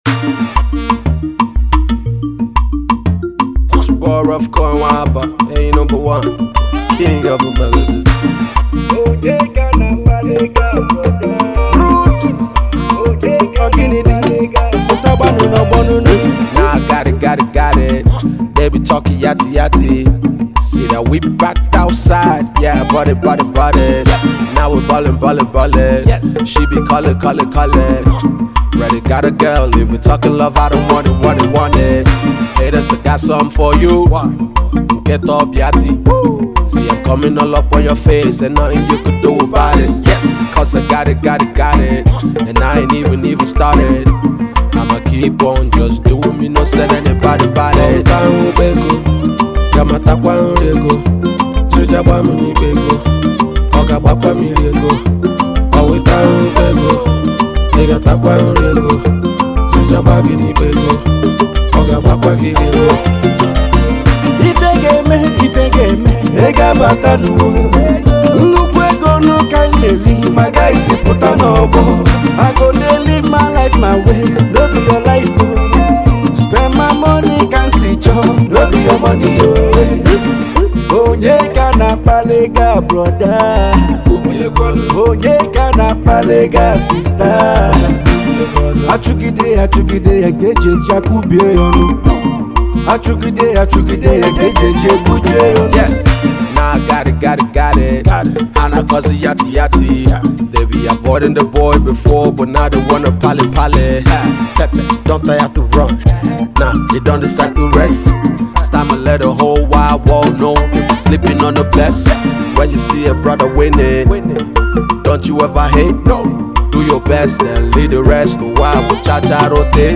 Alternative Rap
a lively uptempo single